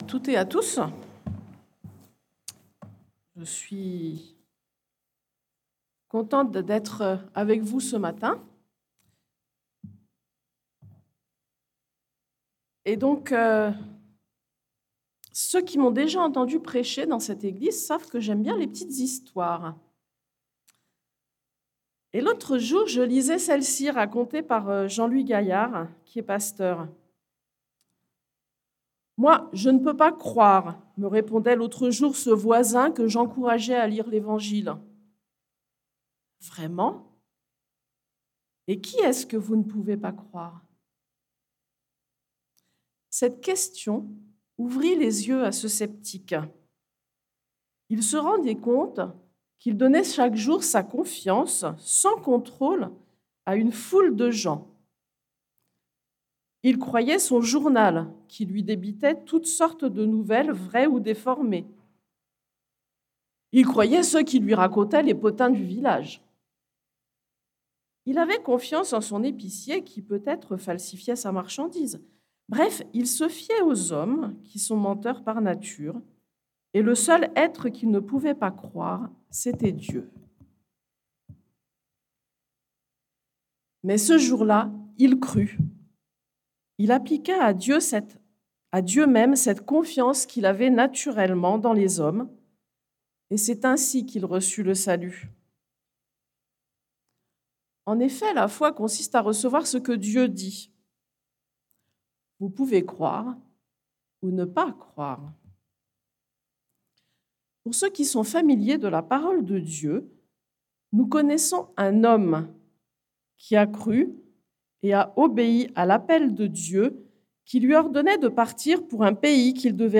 4.1. Prédications - Eglise Evangélique Baptiste